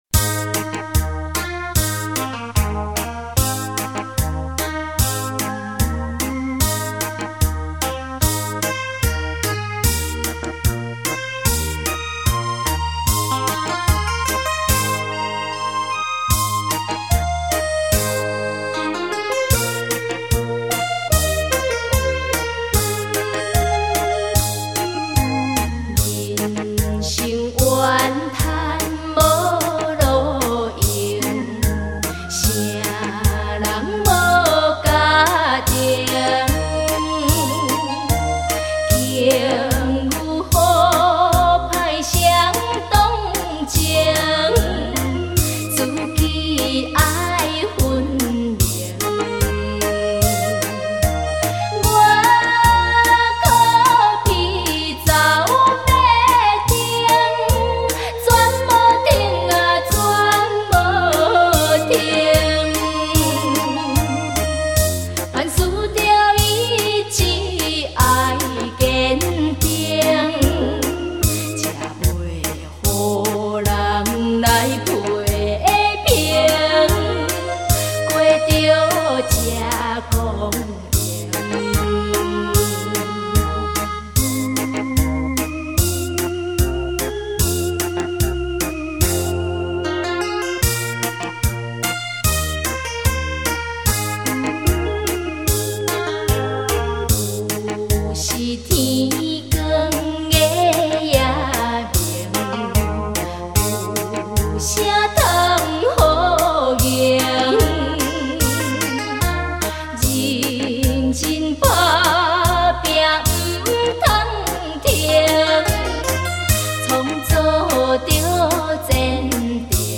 轻快的编曲 表现青少年动的玩味音乐